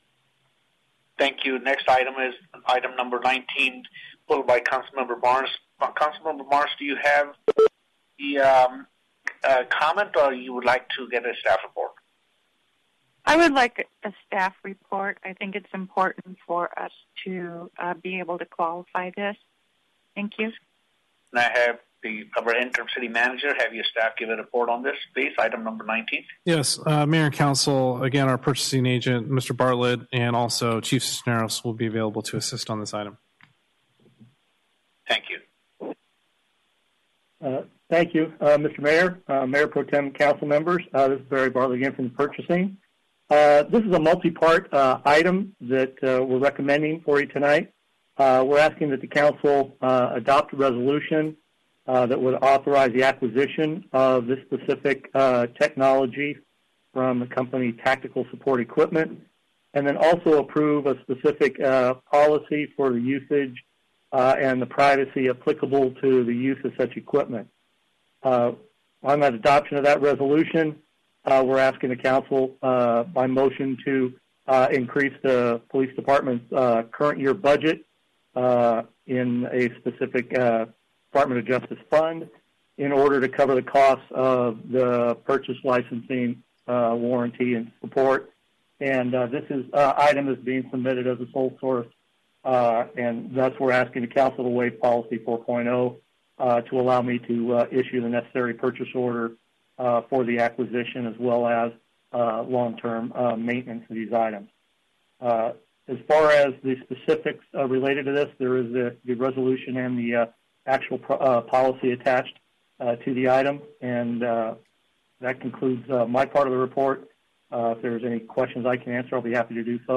Tactical Support Equipment Sole Source Letter – February 26, 2020 Anaheim Purchase Order – June 11, 2020 Tactical Support Equipment Invoice – June 26, 2020 Audio of the CSS agenda item discussion by the Anaheim City Council on June 9, 2020